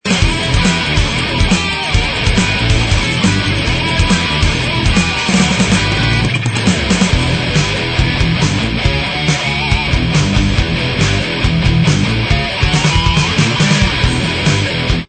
heavy métal